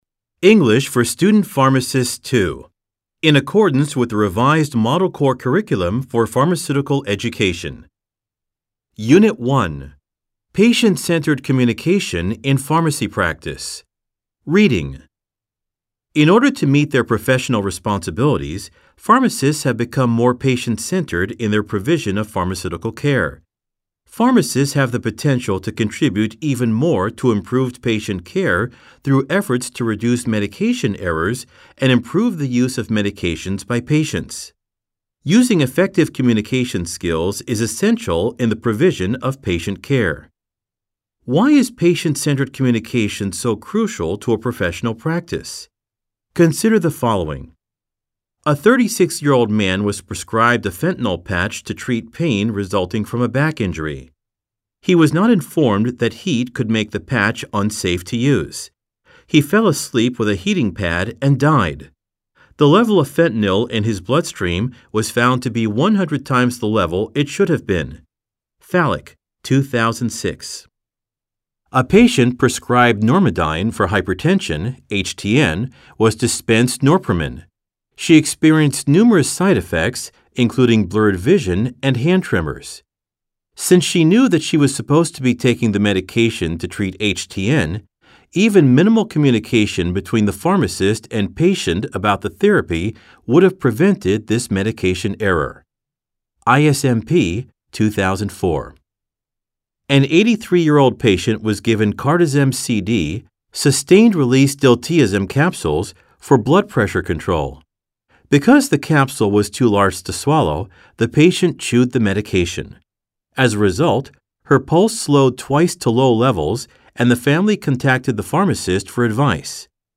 吹き込み Amer E ／ Brit E